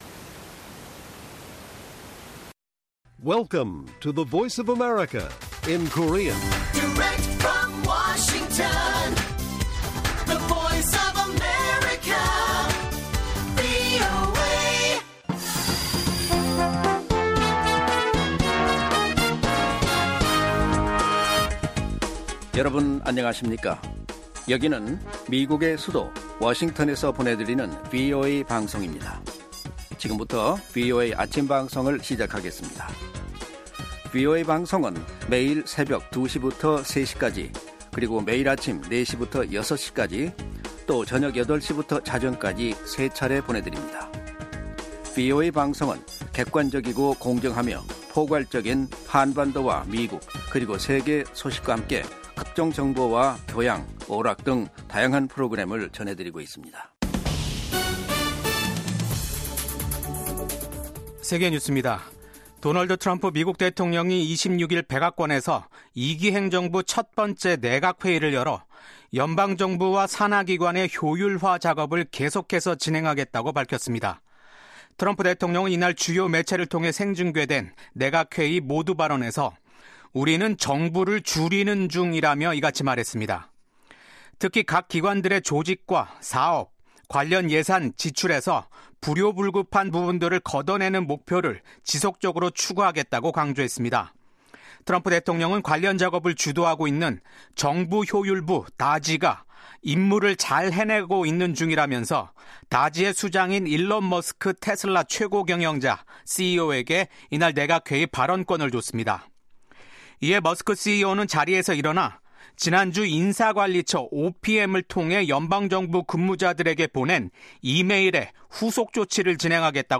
세계 뉴스와 함께 미국의 모든 것을 소개하는 '생방송 여기는 워싱턴입니다', 2025년 2월 27일 아침 방송입니다. 미국과 우크라이나 간 광물 협정 체결이 임박한 것으로 알려진 가운데 볼로디미르 젤렌스키 우크라이나 대통령이 28일 미국을 방문합니다. 미국 연방 하원이 대규모 감세와 정부 지출을 삭감하는 내용을 포함한 예산 결의안을 통과시켰습니다.